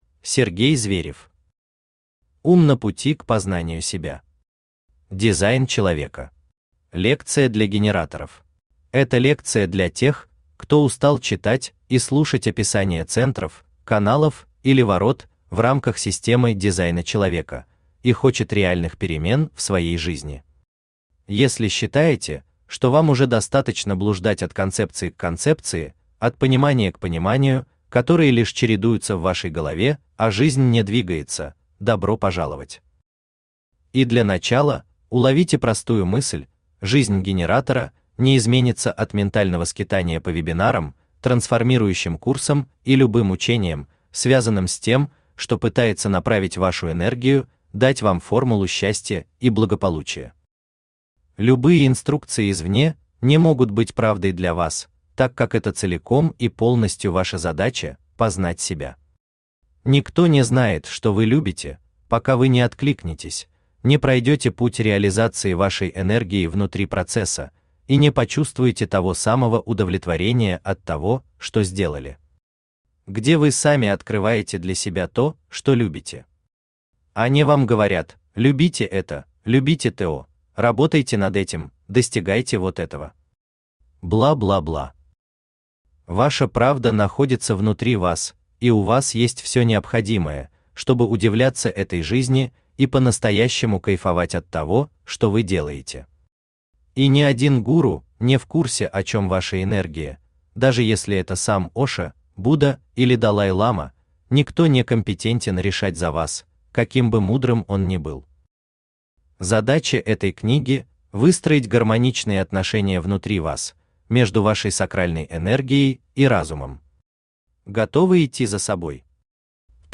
Аудиокнига Ум на пути к познанию себя. Дизайн Человека. Лекция для генераторов | Библиотека аудиокниг